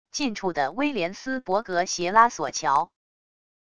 近处的威廉斯伯格斜拉索桥wav音频